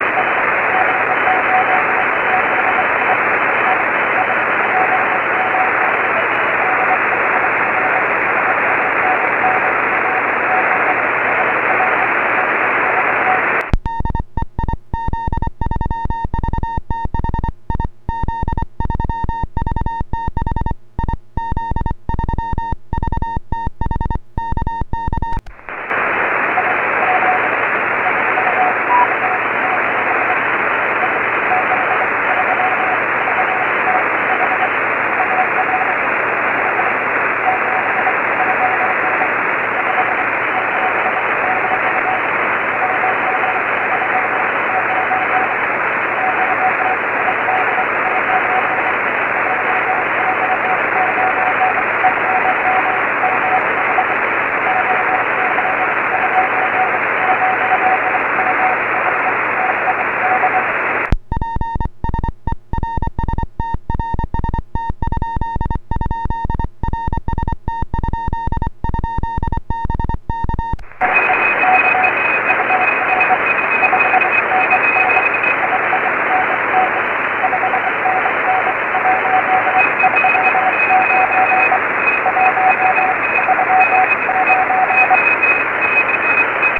IARU VHF 09/2014 CATEGORIA 6 ORE